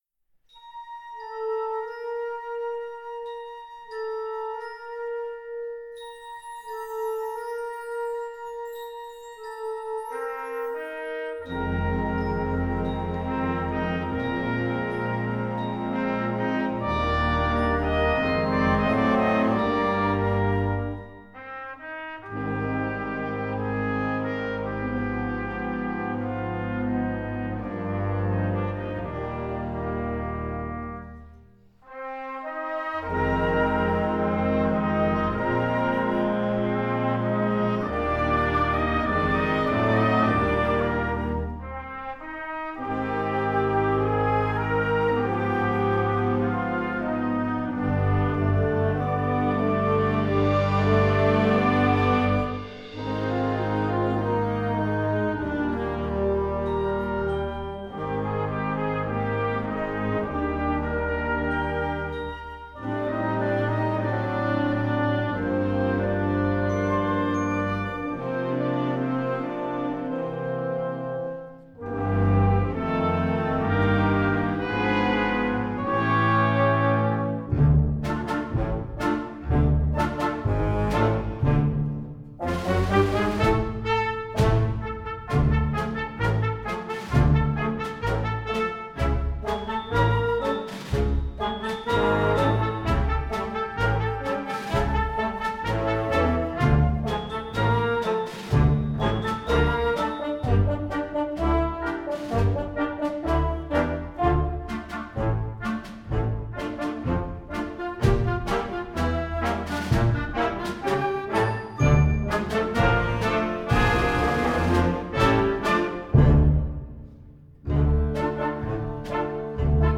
Gattung: Filmmusik für Jugendblasorchester
Besetzung: Blasorchester